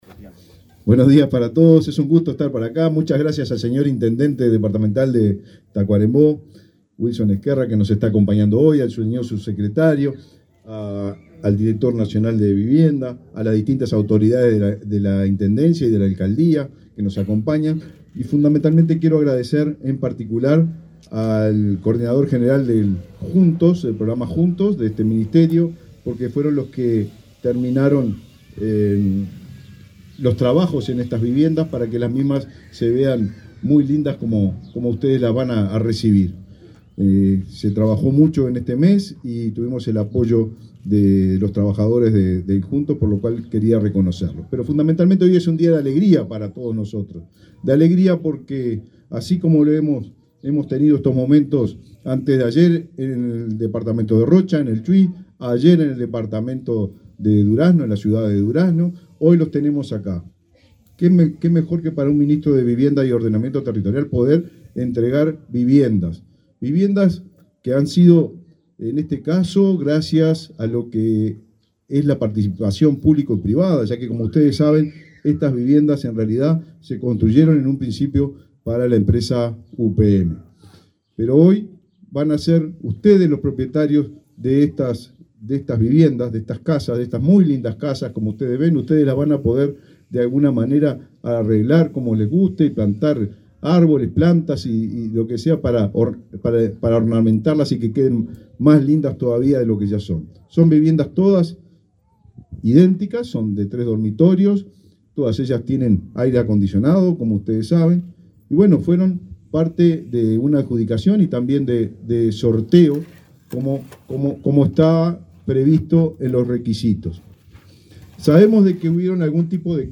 Palabras del ministro de Vivienda, Raúl Lozano
Este viernes 24, el ministro de Vivienda, Raúl Lozano, participó del acto de entrega de 52 viviendas en Paso de los Toros, departamento de Tacuarembó.